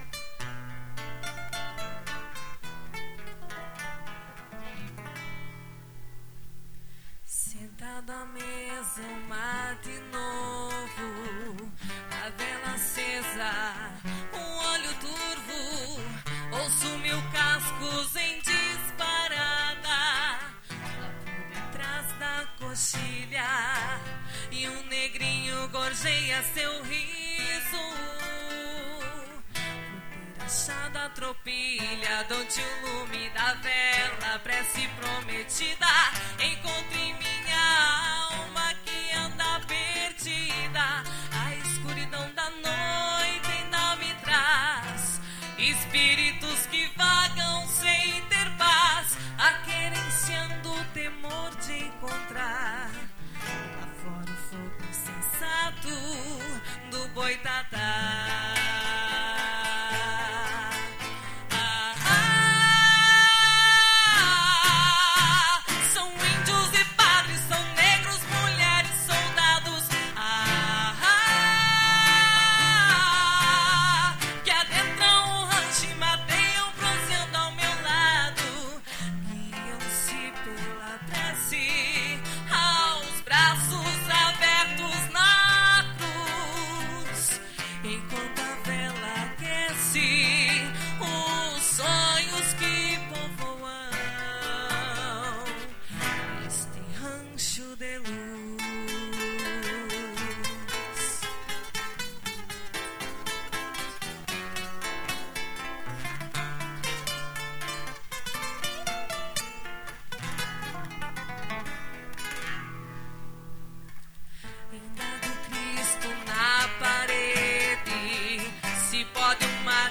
A final do 3º Canto do Barril da Canção Gaúcha aconteceu neste sábado, 19, em Frederico Westphalen e contagiou as centenas de pessoas que se fizeram presentes. Os cantores de diversos municípios da região subiram ao palco pela segunda vez esta semana, disputando a final do festival.
Acima de 14 anos solo: